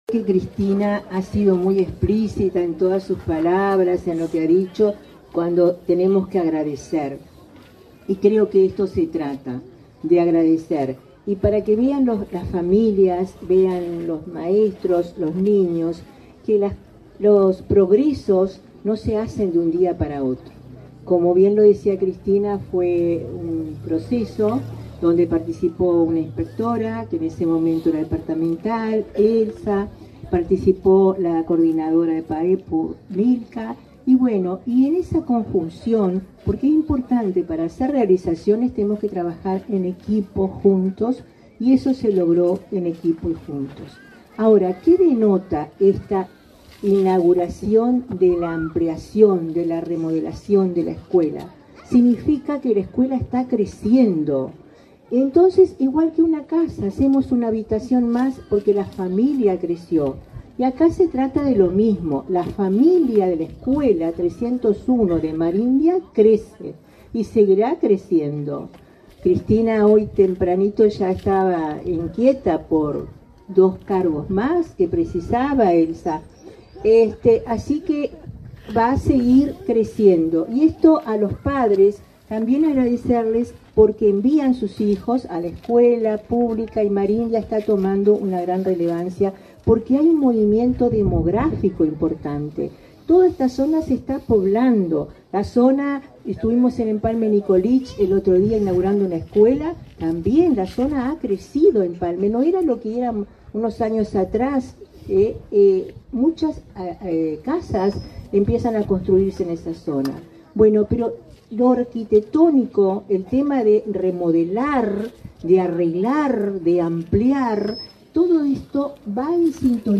Acto por la inauguración de obras en escuela de Marindia